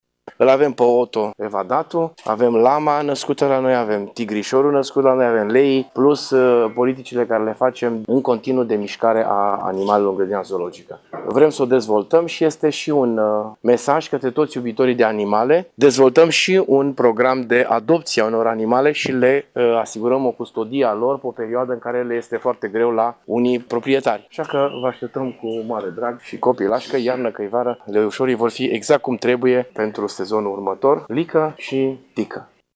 Primarul a explicat că a devenit o tradiție ca puii să fie botezaţi, iar numele alese trebuie să fie cât mai ușor de pronunțat de către copii: